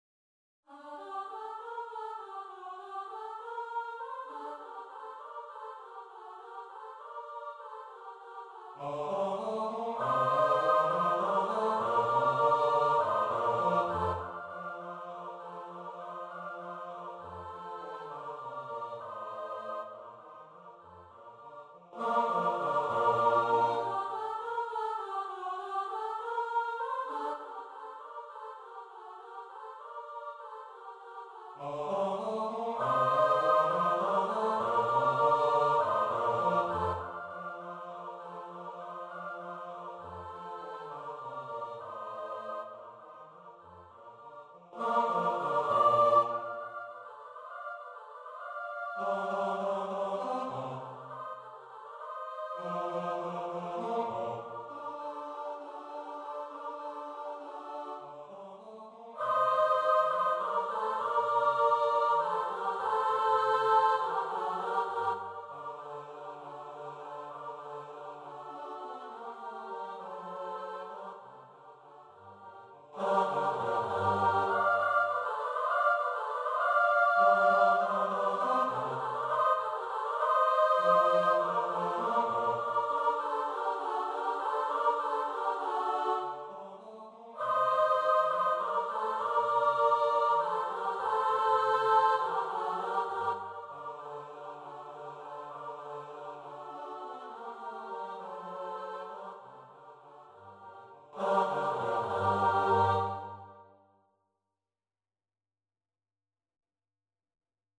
Katalog utworów na trzy głosy mieszane według kompozytorów
1318_midi_martini_campana_che_suona.mp3